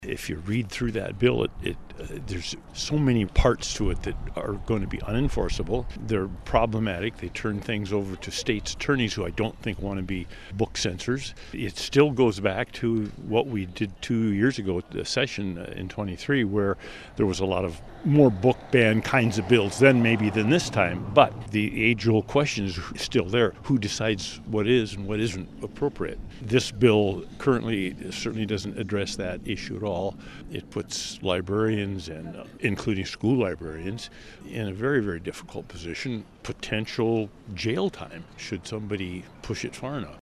One of the protesters of Senate Bill (SB) 2307 is former State Representative Phil Mueller.